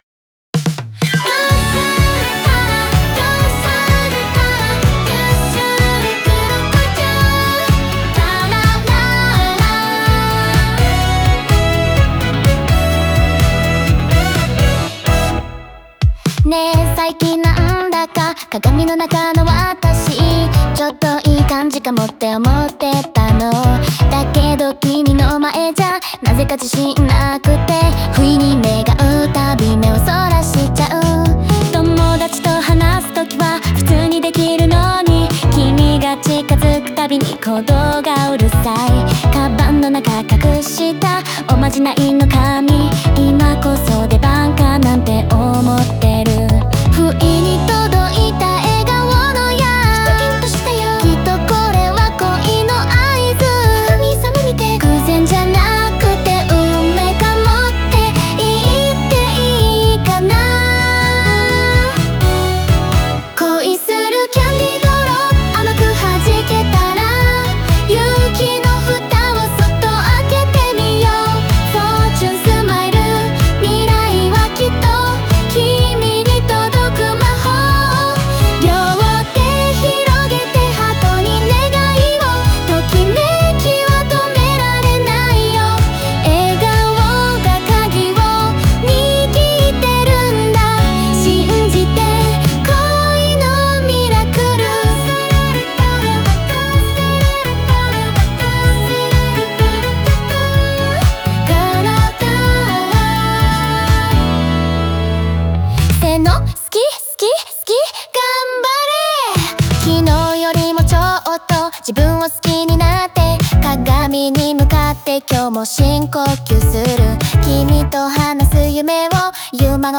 明るくノリのよいディスコ調のリズムと、前向きな歌詞が聴く人の背中をそっと押してくれる。